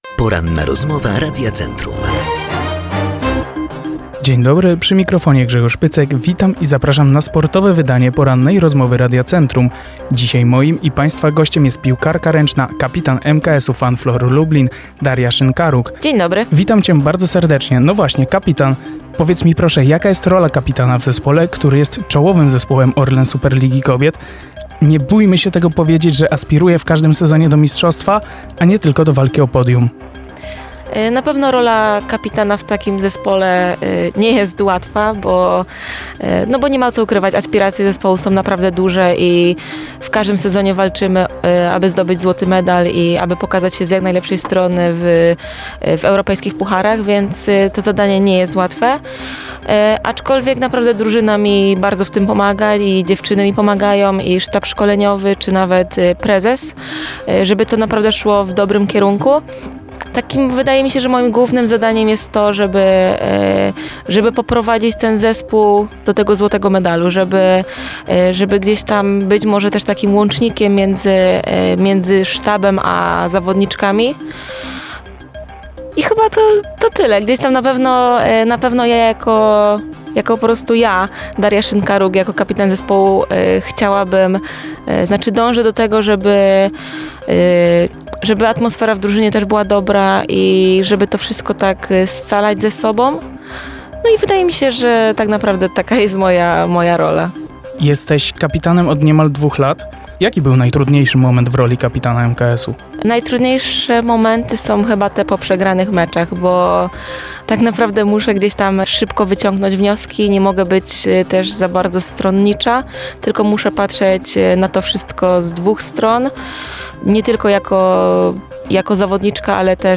ROZMOWA.mp3